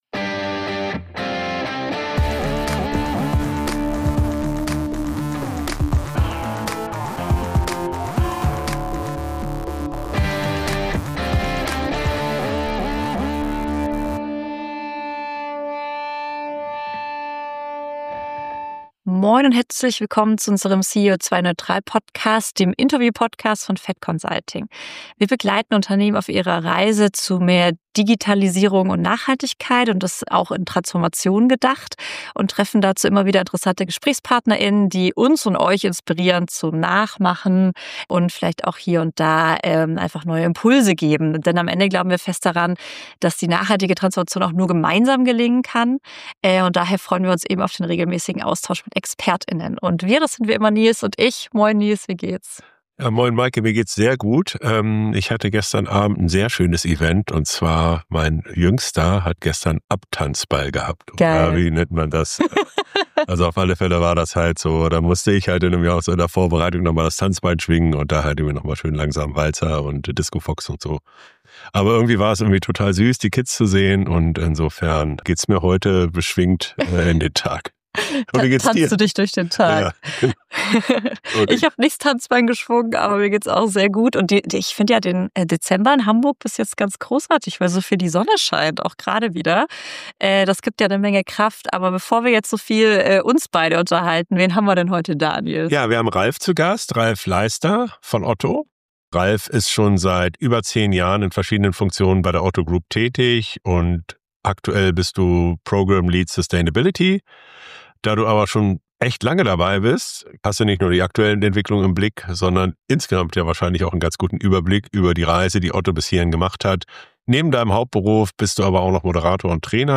CEO2-neutral - Der Interview-Podcast für mehr Nachhaltigkeit im Unternehmen